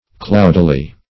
cloudily - definition of cloudily - synonyms, pronunciation, spelling from Free Dictionary Search Result for " cloudily" : The Collaborative International Dictionary of English v.0.48: Cloudily \Cloud"i*ly\, adv. In a cloudy manner; darkly; obscurely.